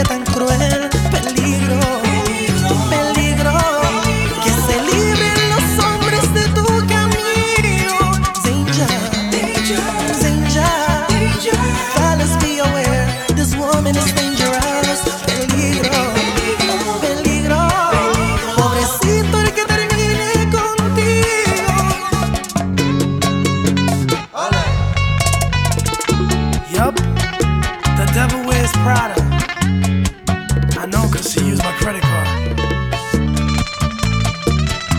# Salsa and Tropical